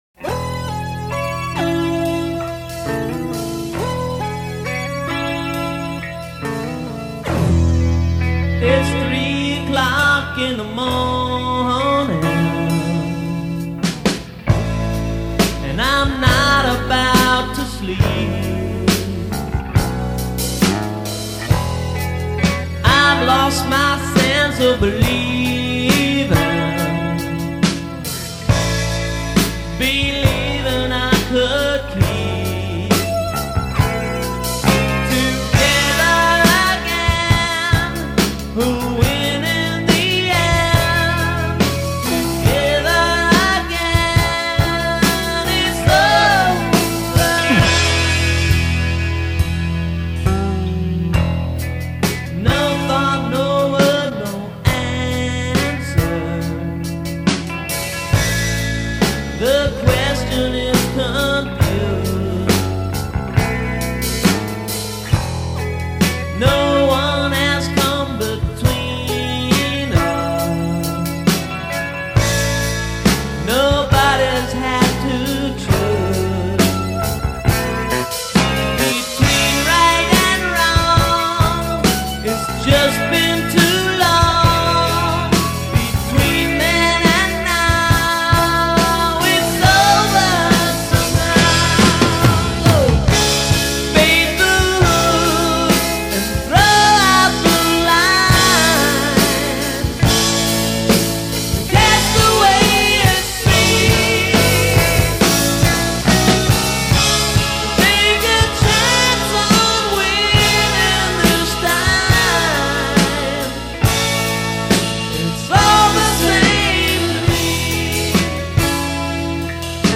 Drums.
Lead Vocal and Rhythm Guitar.